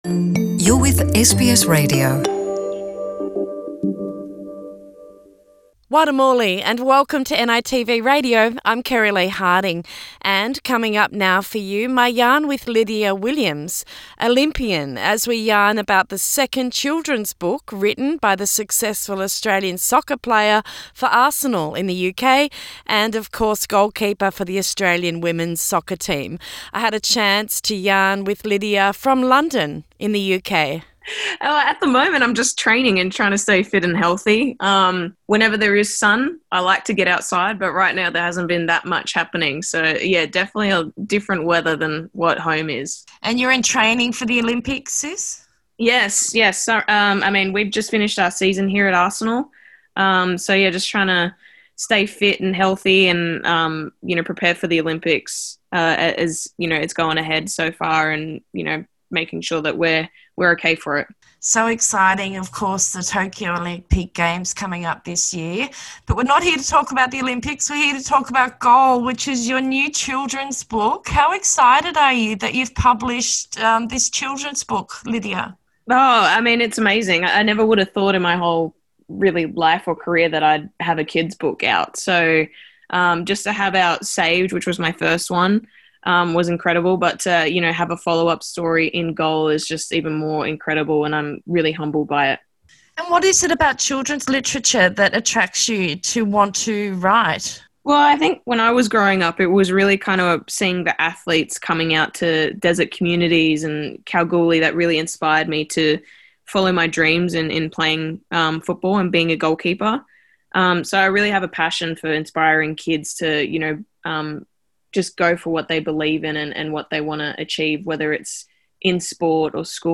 A new children’s book is out now and is the second young people’s book written by Olympian and Soccer player for the Australian women’s team the Matlilda’s. Interview with Lydia Willliams – Olympian and International soccer star